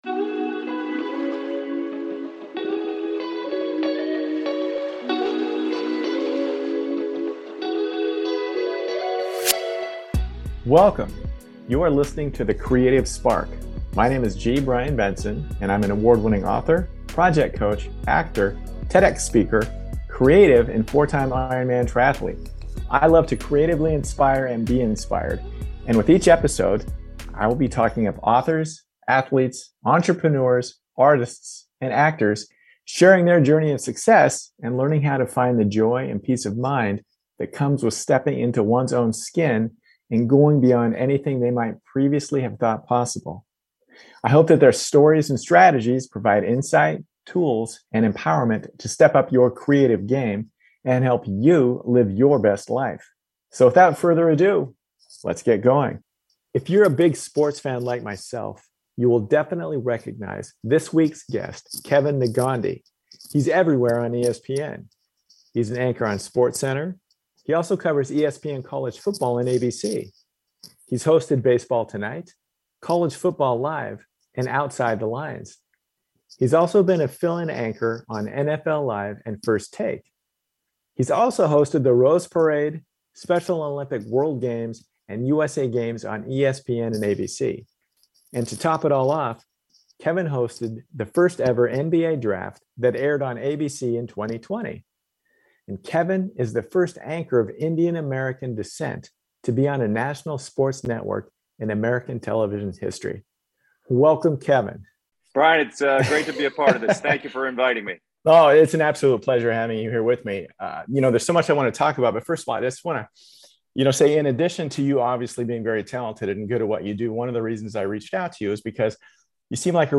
With Guest Kevin Negandhi - ESPN Sportscenter Anchor & Host
Join me as I chat Kevin up about his fantastic work on ESPN, his creative process as well as his role as a trailblazer in the sports journalism field.